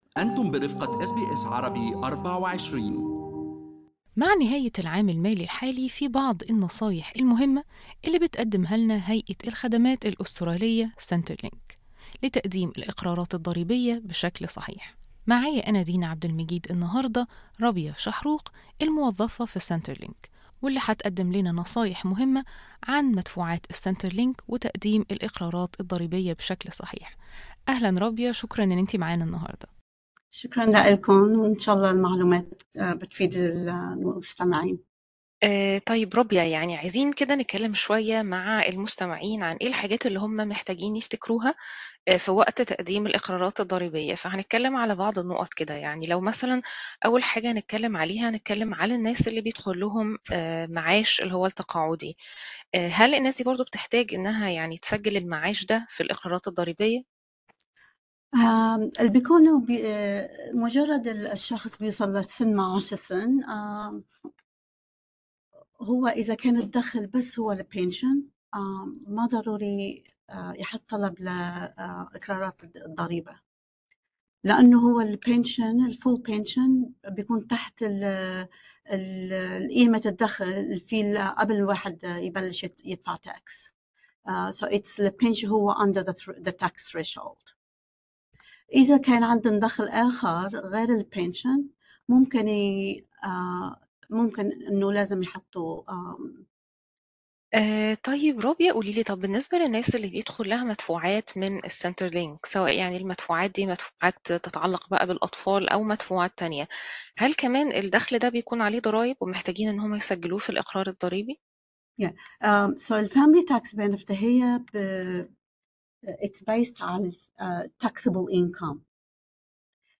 للاستماع للمقابلة الصوتية كاملة يمكنكم الضغط على الرابط أعلاه.